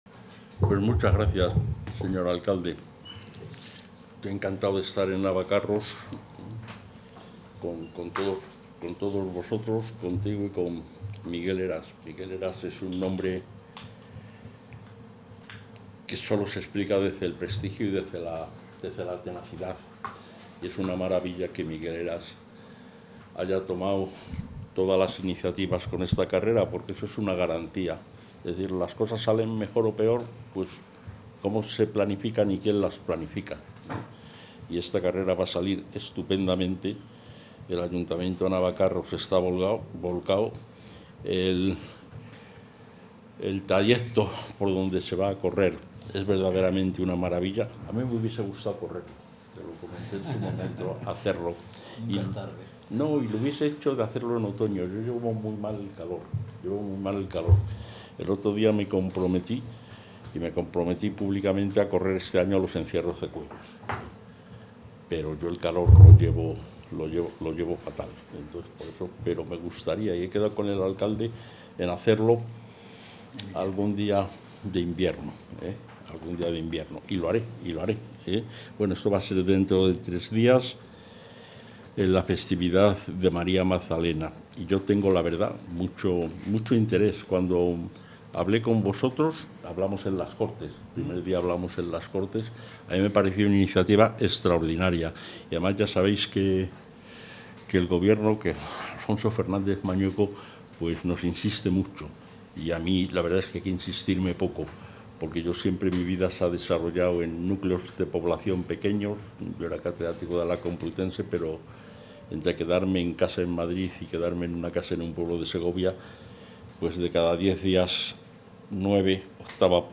El consejero de Cultura, Turismo y Deporte, Gonzalo Santonja, ha presentado hoy, en la localidad salmantina de Navacarros, la nueva iniciativa,...
Intervención del consejero.